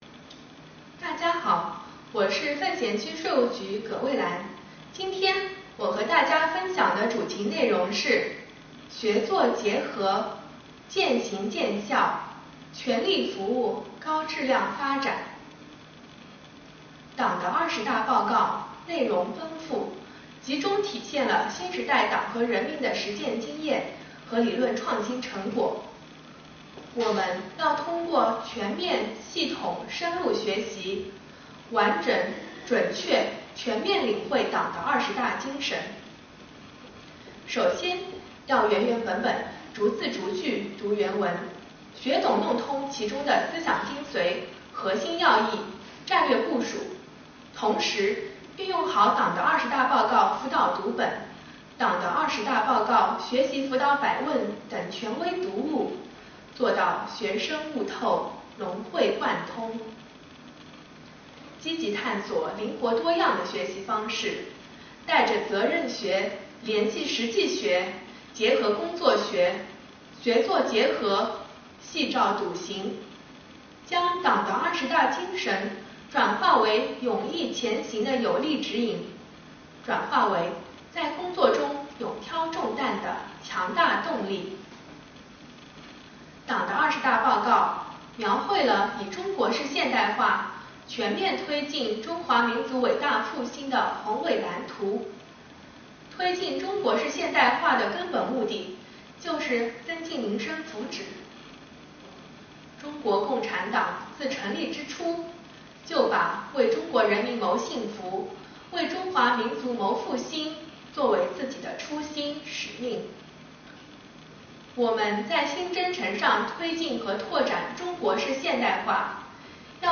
为进一步深入学习宣传贯彻党的二十大精神，加强青年理论武装，奉贤区税务局结合当前重点税收工作和中心任务，组建青年宣讲团，开展青年理论学习小组“初心讲堂”宣讲活动，教育引导广大青年干部用党的二十大精神指导实践，以实际行动推动各项工作落地生根、开花结果。